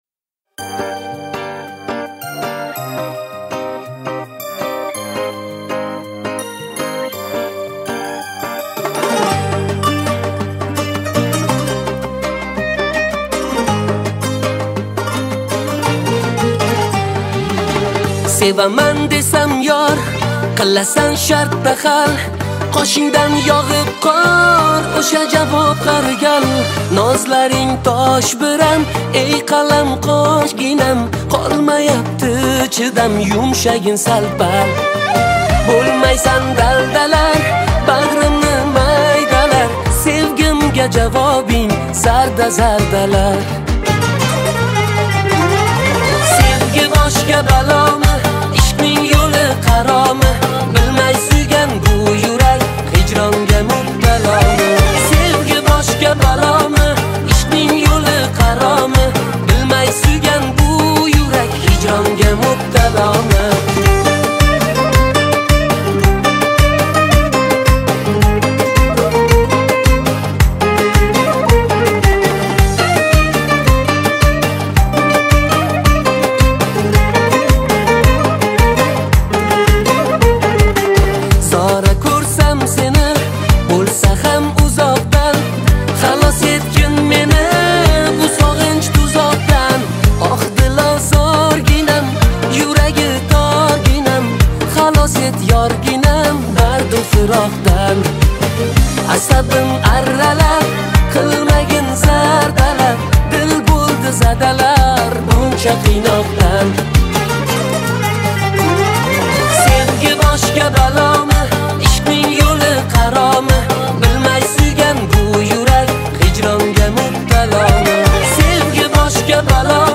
Узбекская песня